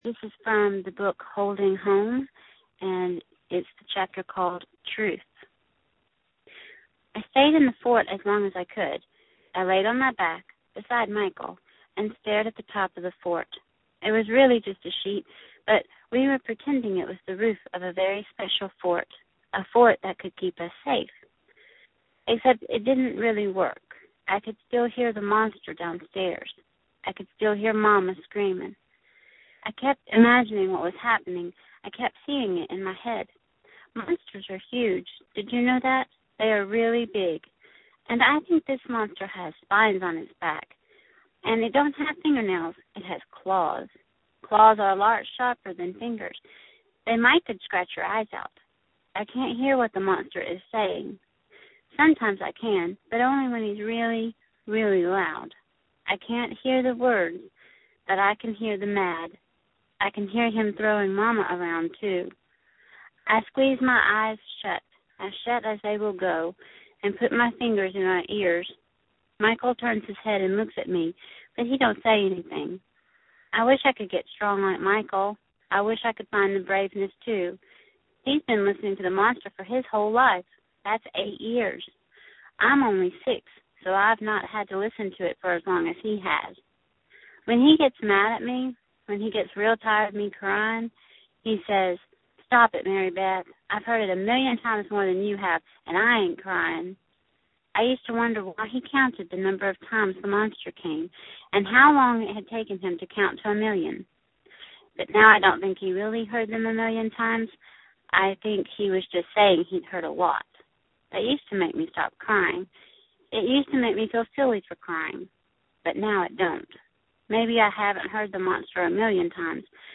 An audio excerpt from “Holding Home” that I read last night and cried over.